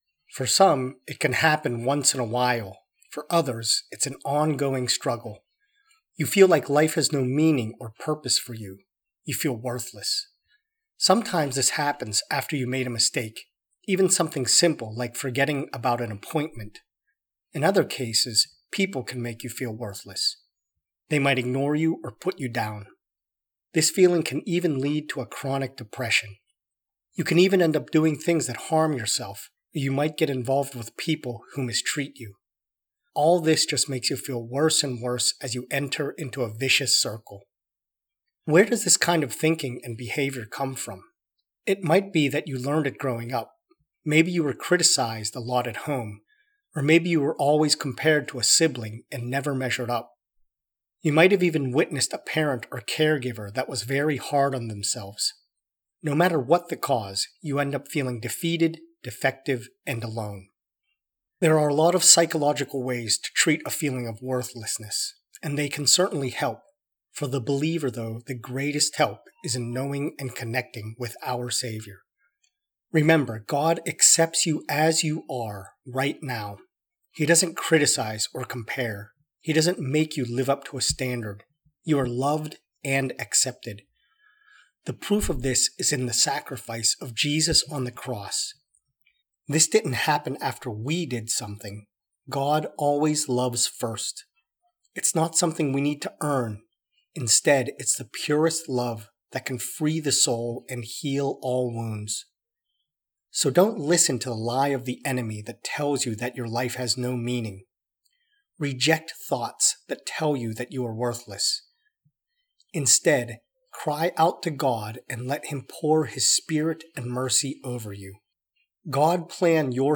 prayer-for-when-you-feel-worthless.mp3